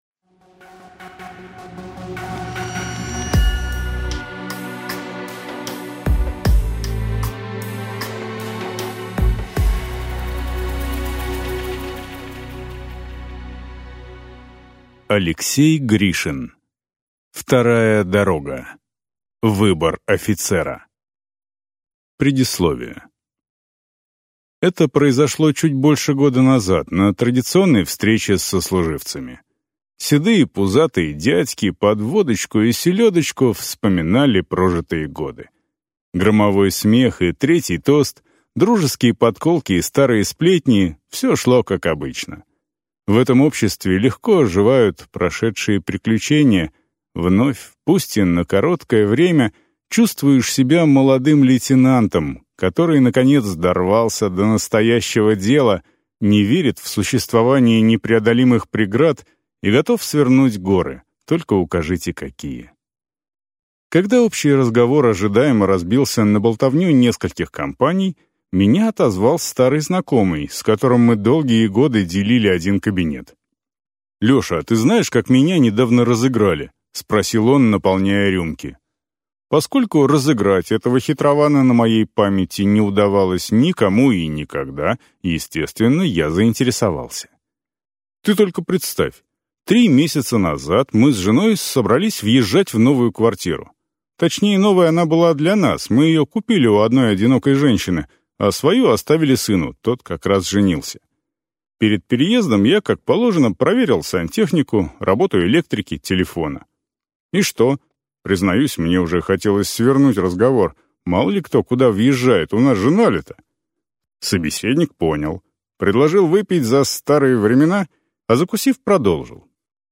Аудиокнига Выбор офицера | Библиотека аудиокниг